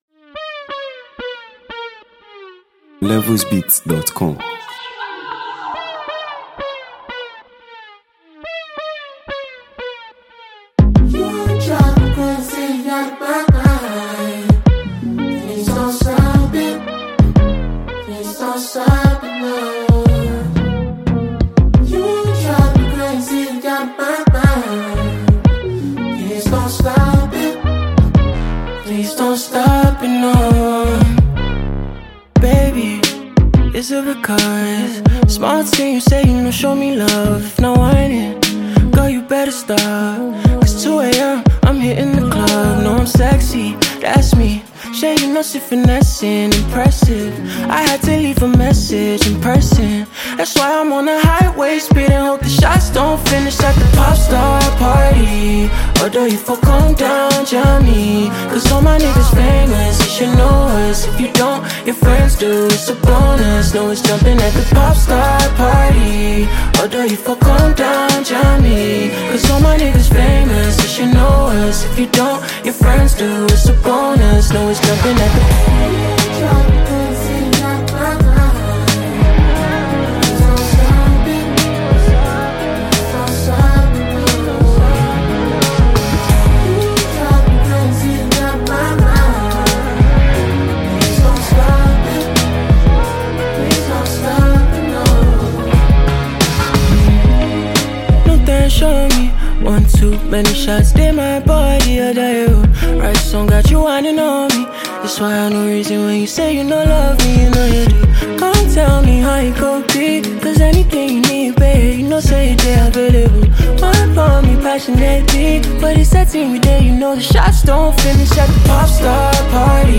a vibrant and captivating record